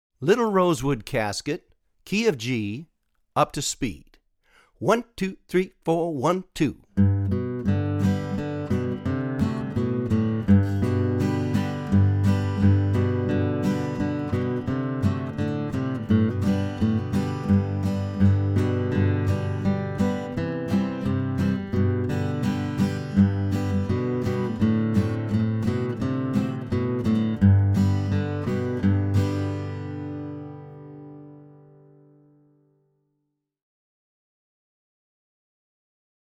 DIGITAL SHEET MUSIC - FLATPICK GUITAR SOLO
Online Audio (both slow and regular speed)